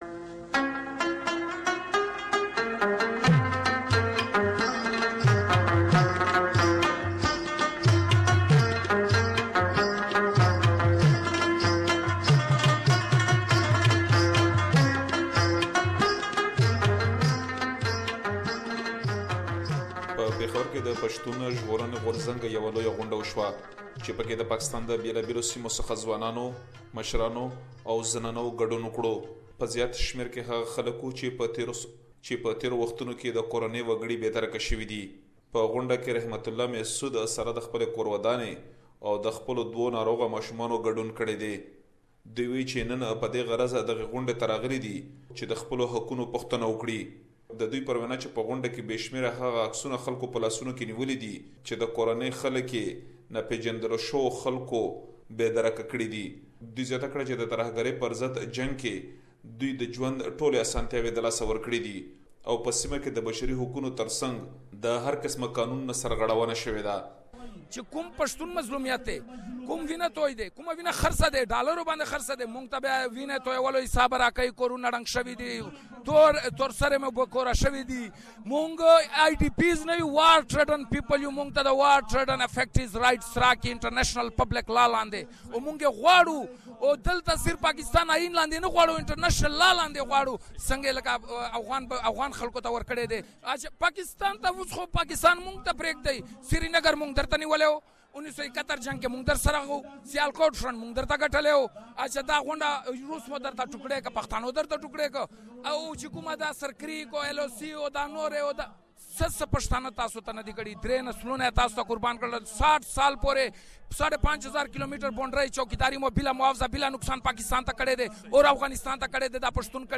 Report from inside PTM’s Peshawar gathering